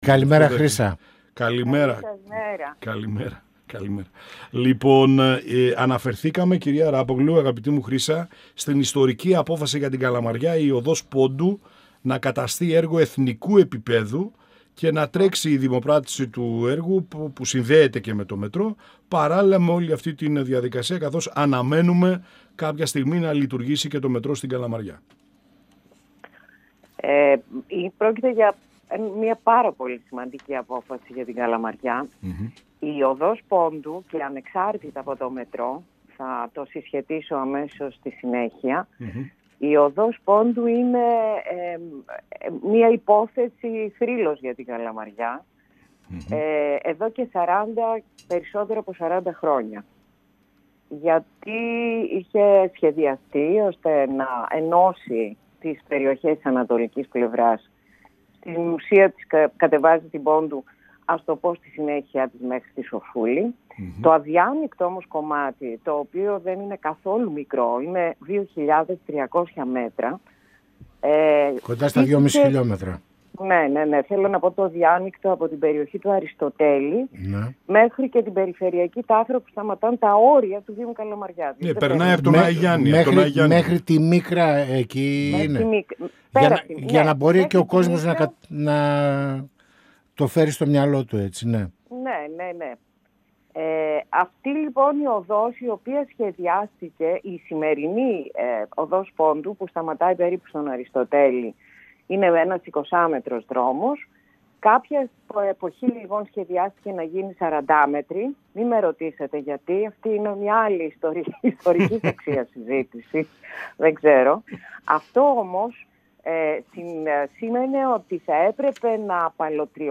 Η Δήμαρχος Καλαμαριάς Χρύσα Αράπογλου στον 102FM της ΕΡΤ3 | «Πανόραμα Επικαιρότητας» | 22.02.2026